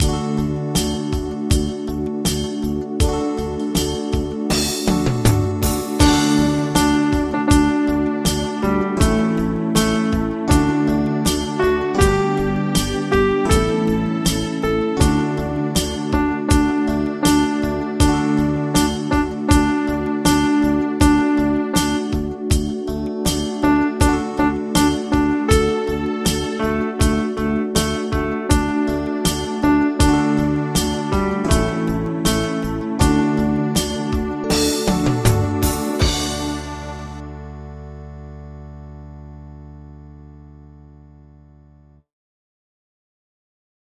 Audio Midi Bè Bass: download